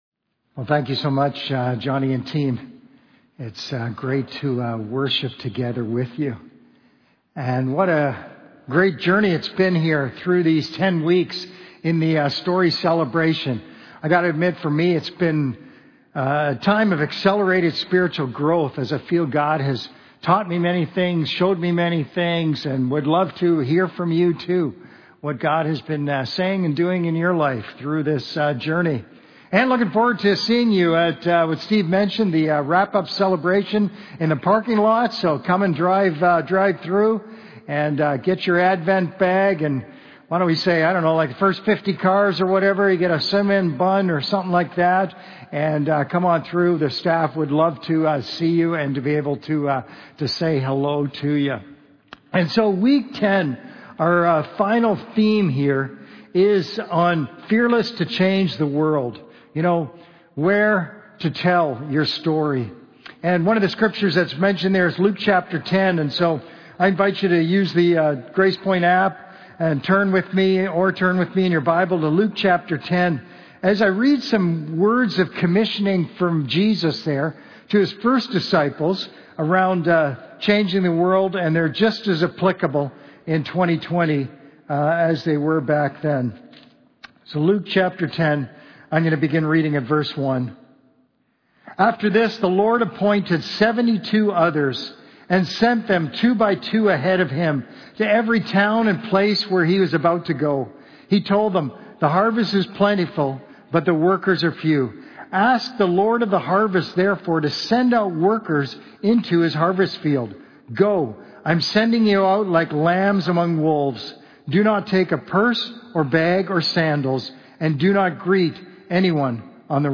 Sermons | Gracepoint Community Church